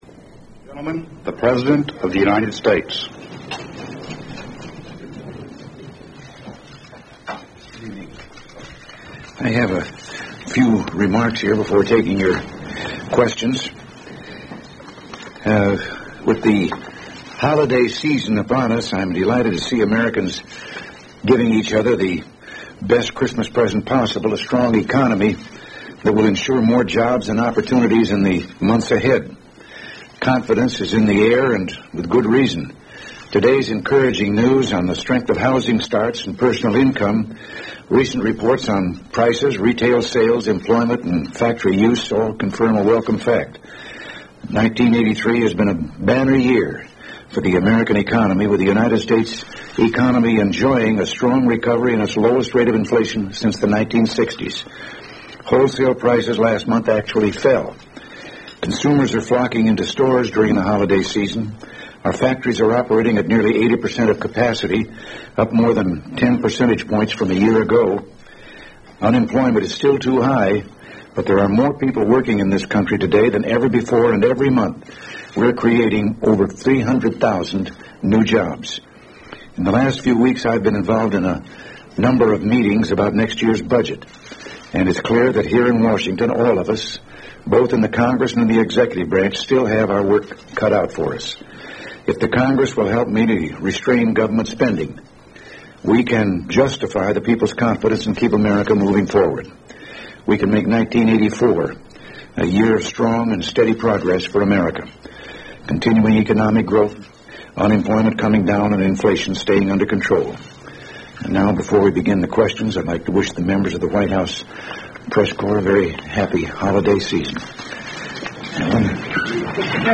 U.S. President Ronald Reagan delivers a press conference, speaks on the economy